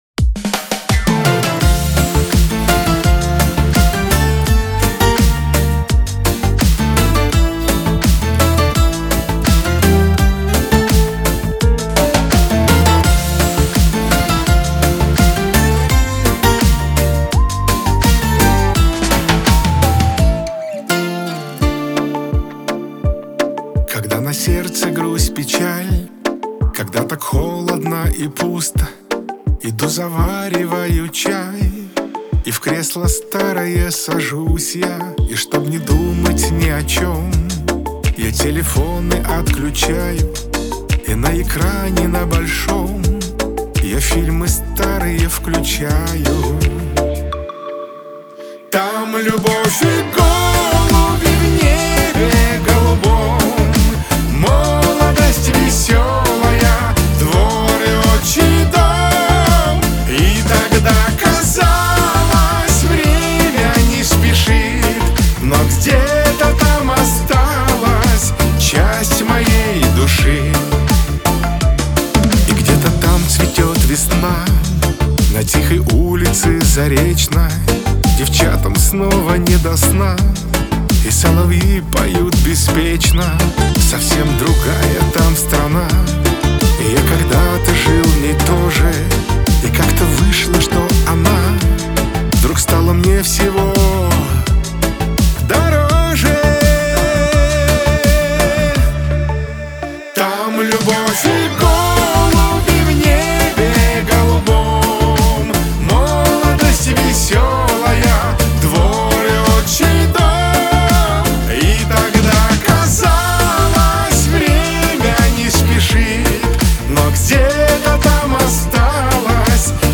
pop
эстрада , диско